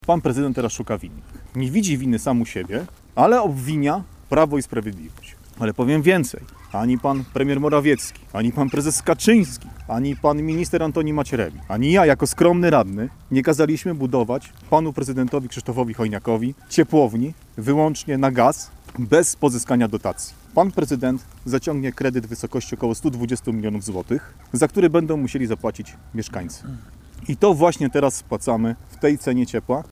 Dziś (21 stycznia) w Piotrkowie odbyła się konferencja prasowa piotrkowskich radnych Prawa i Sprawiedliwości.
Konferencja prasowa PiS w Piotrkowie